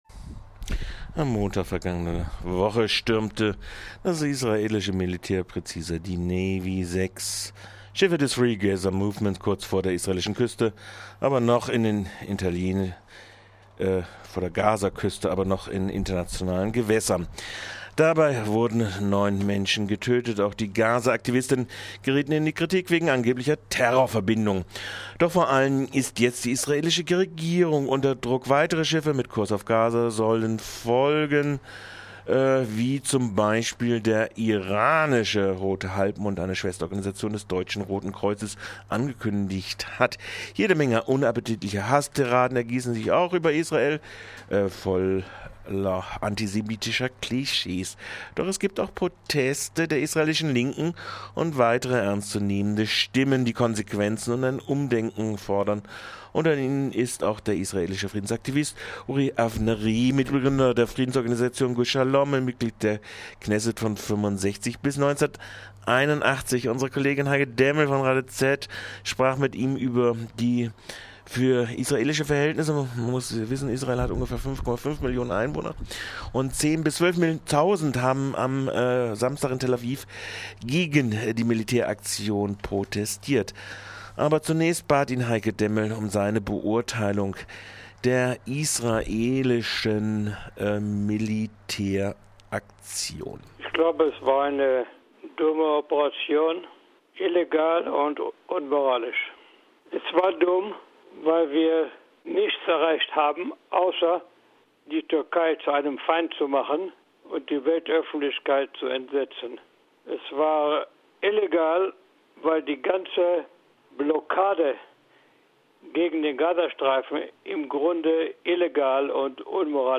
Das sagt der israelische Friedensaktivist Uri Avnery zur Stürmung der Gaza Flotte vor gut einer Woche. Wir baten ihn heute um eine Beurteilung des umstrittenen Militäreinsatzes, aber auch um einen Bericht zur Stimmung in der israelischen Bevölkerung und den Friedensprotesten in Israel vom Wochenende.